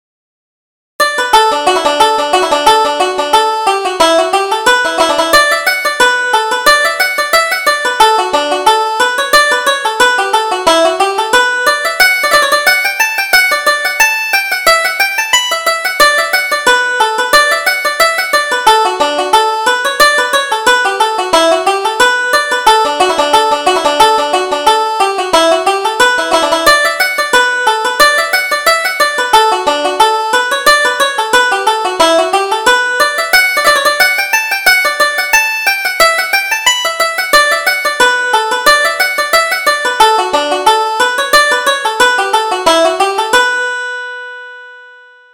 Reel: Lord Gordon's Reel